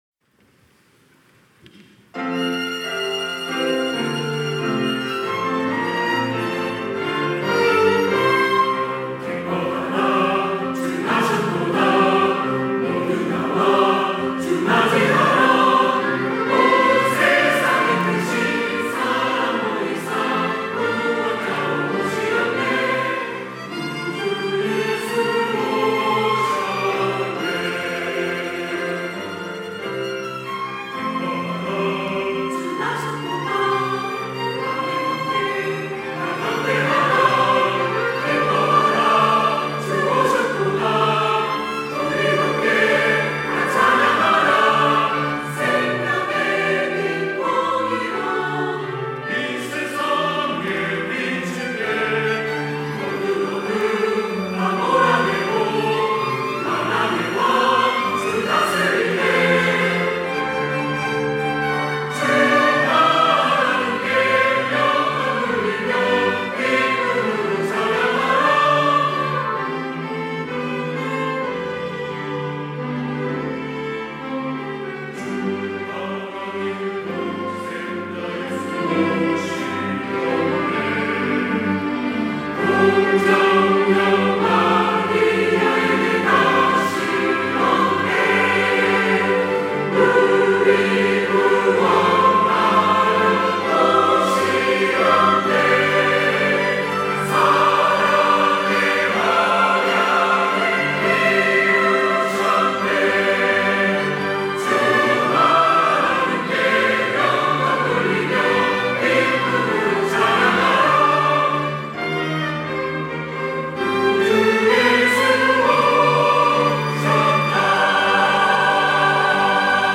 호산나(주일3부) - 기뻐하라
찬양대